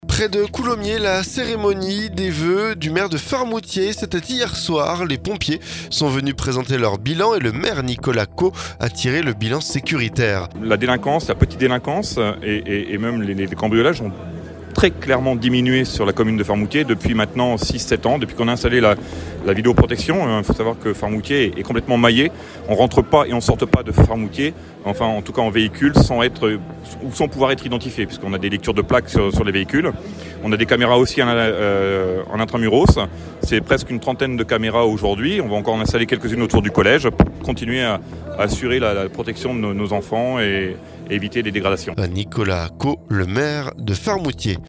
La cérémonie des vœux du maire de Faremoutiers...